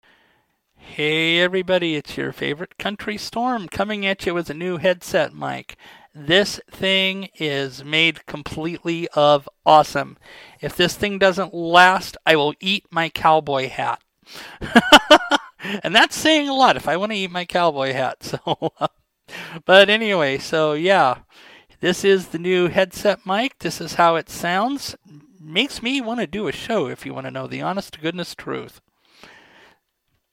This is the sound of my new headset mic!